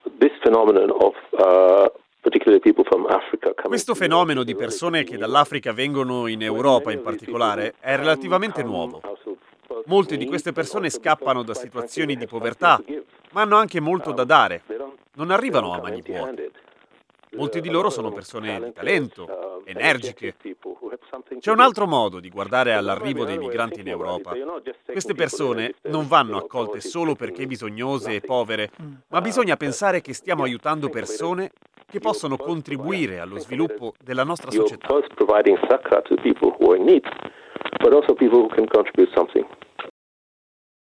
GURNAH-1930-AUDIO-TRADOTTO.mp3